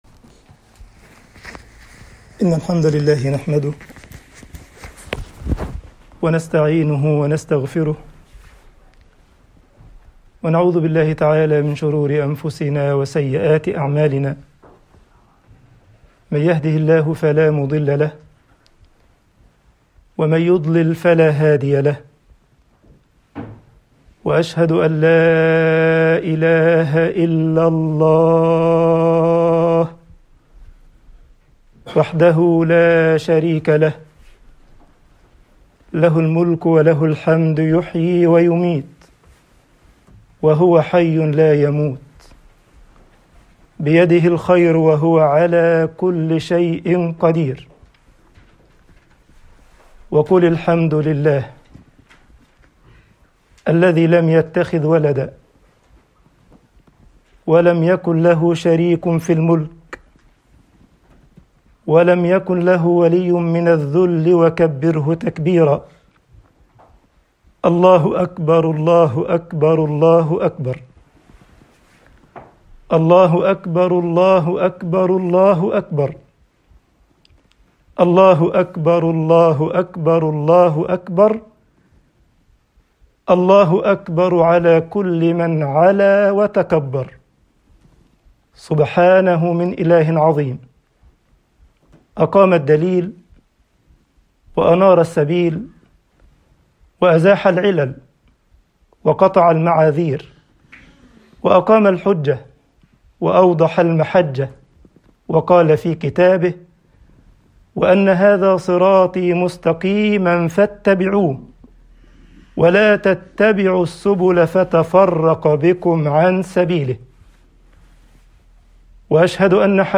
لماذا الفرحة بالعيد؟ خطبة عيد الفطر 1441هـ